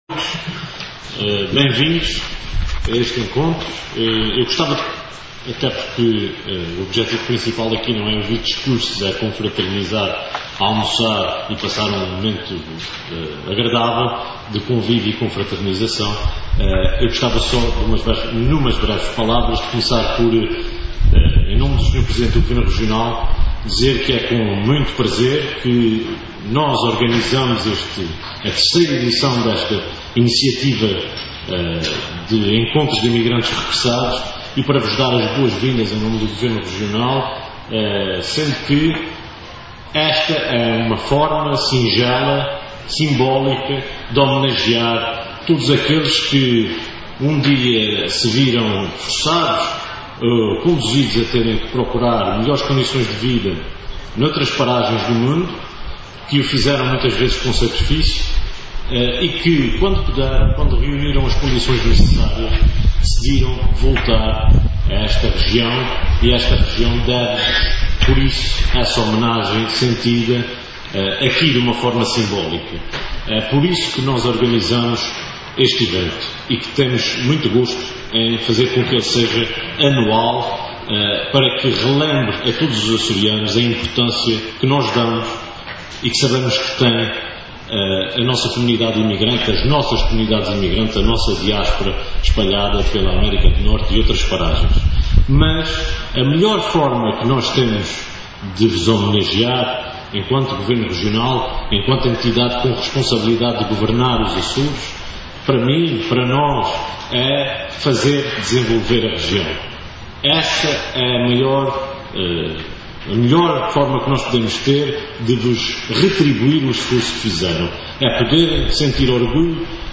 A opinião foi expressa por André Bradford na abertura do III Encontro de Emigrantes Regressados, a cuja cerimónia presidiu esta manhã, na Marina da Horta, em representação do Presidente do Governo.